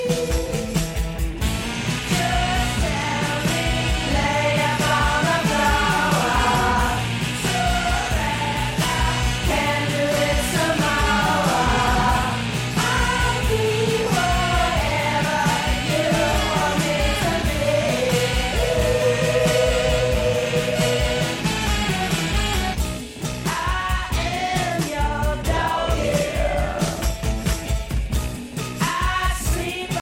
Rock para quem não é dono do seu destino.